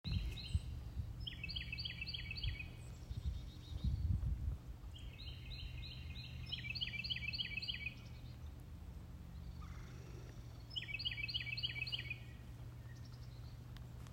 Morning Sounds
morning-bird-run-1.25.20.m4a